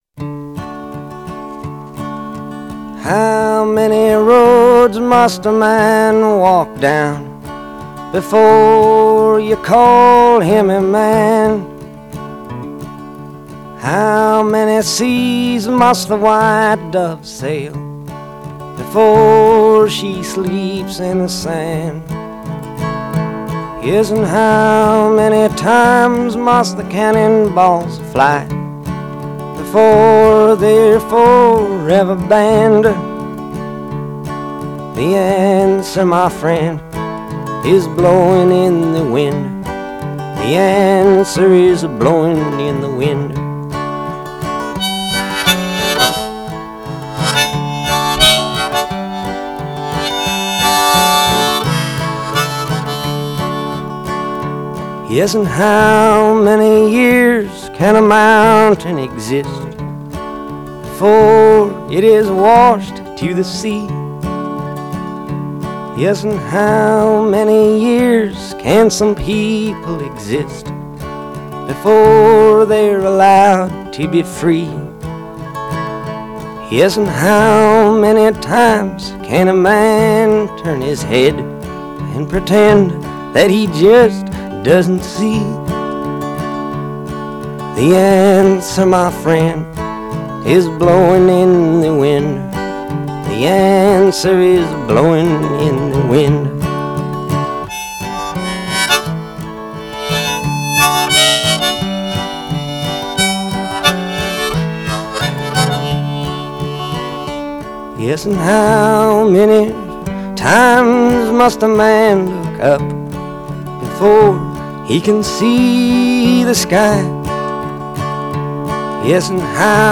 风格流派: Folk
那强劲而粗放的、几乎不加修饰的吉它弹奏，则融入了布鲁斯音乐的精致风韵。